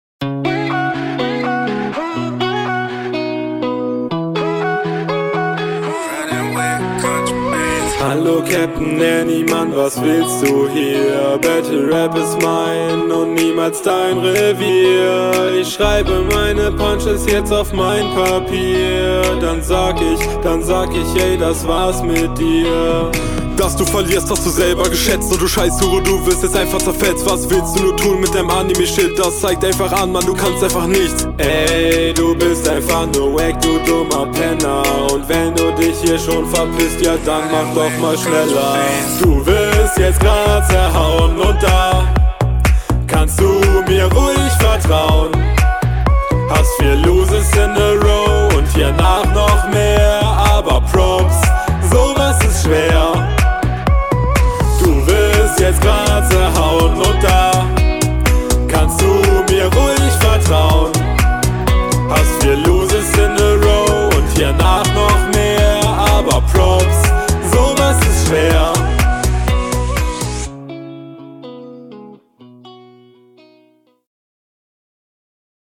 Flow: Flowlich sehr stabil. Mir sind keine großartigen Taktfehler aufgefallen.
Ok Beat ist außergwöhnlich für ein Battle. Sehr poppig.